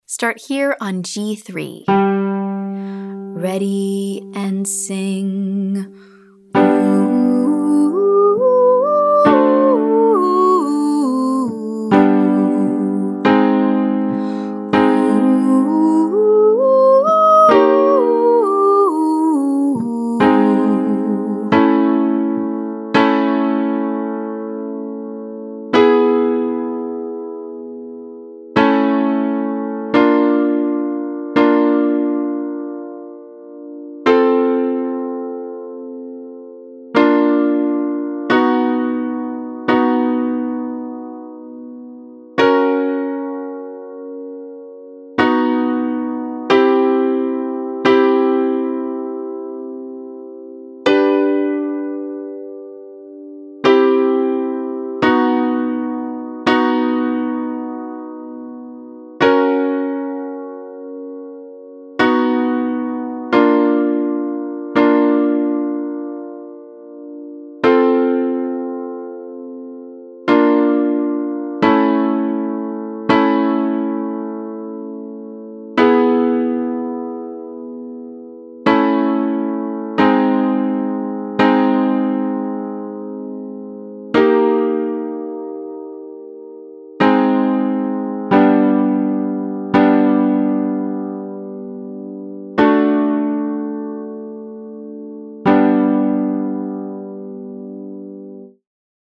1. Exercise 1: Full major scale, ascending & descending. (Syllables: DOO, HOO, OO)
2. Exercise 2: 1.5 octave scale, ascending & descending. (Syllables: DOO, HOO, OO)